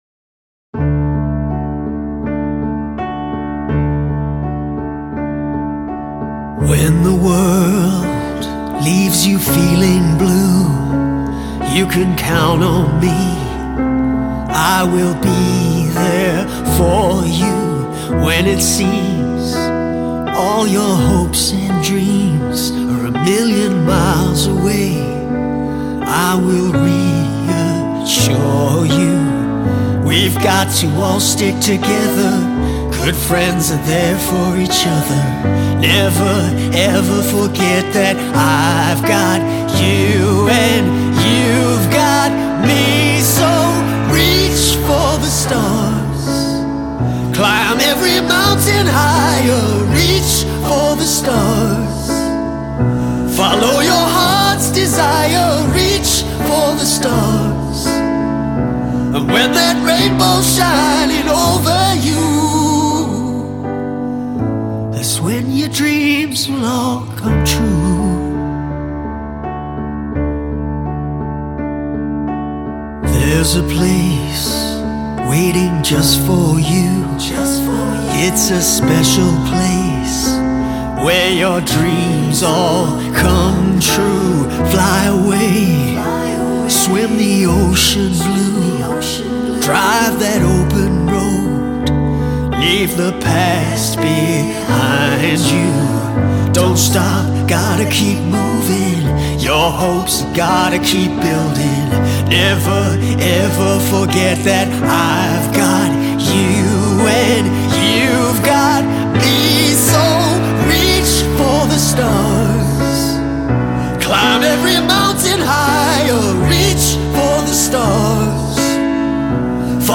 I don’t know why I’ve reimagined it as a piano ballad.